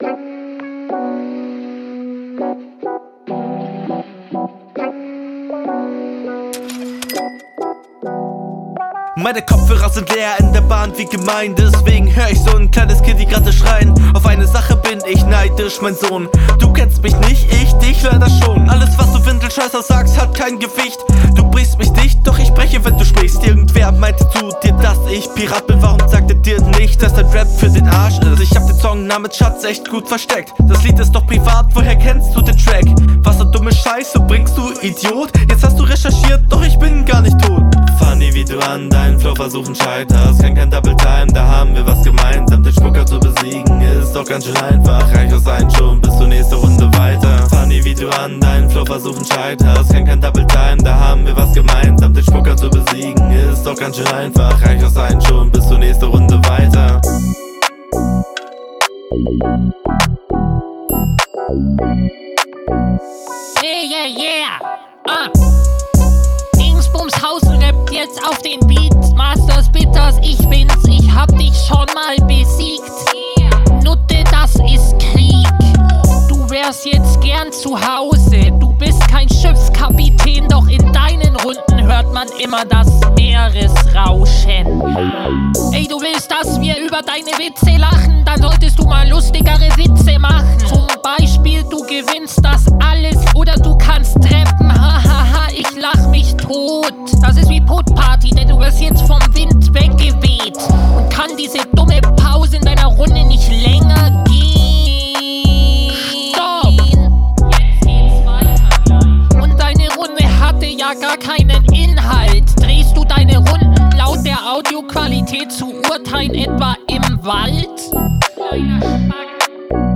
Klingt im Vergleich direkt deutlich angenehmer.